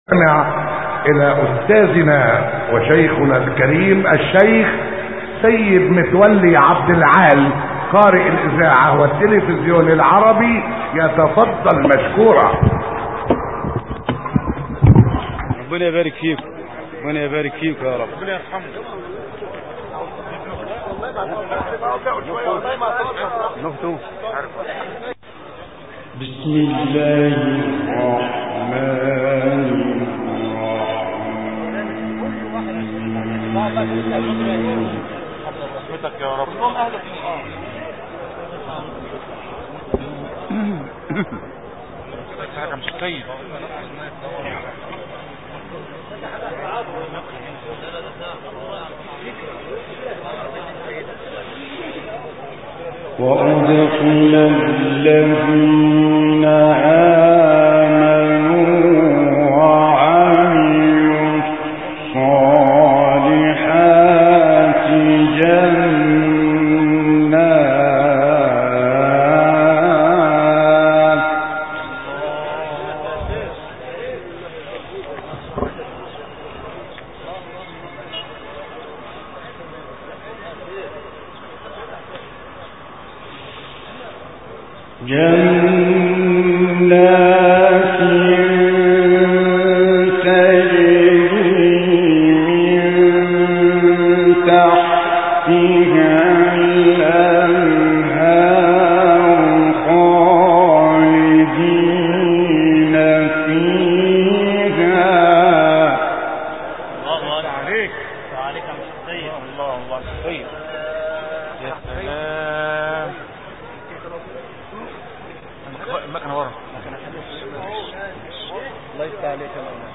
تلاوت عبدالعال در مراسم چهلم «حصان»
گروه فعالیت‌های قرآنی: تلاوت آیاتی از سوره ابراهیم که توسط سیدمتولی عبدالعال در مراسم چهلمین روز درگذشت استاد عبدالعزیز حصان اجرا شده است، ارائه می‌شود.